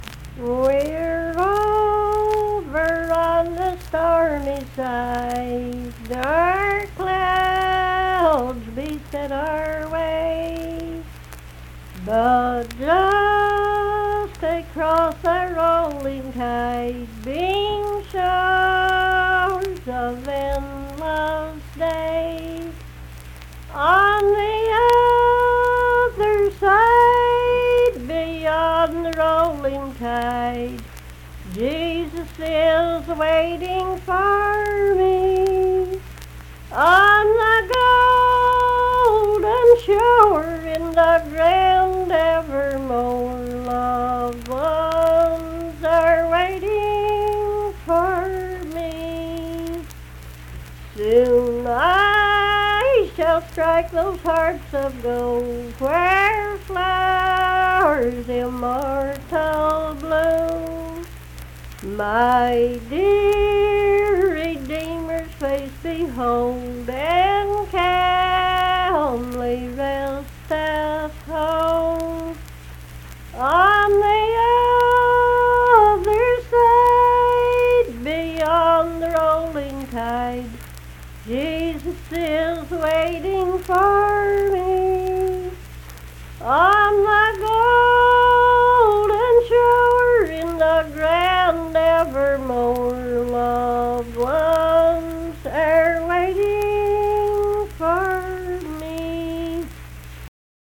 Unaccompanied vocal music performance
Hymns and Spiritual Music
Voice (sung)